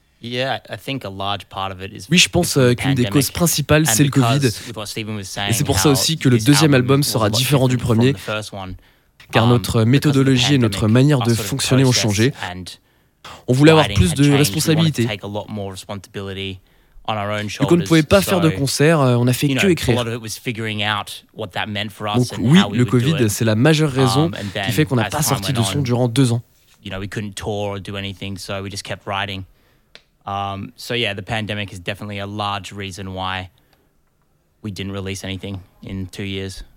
Ils étaient en live depuis nos studios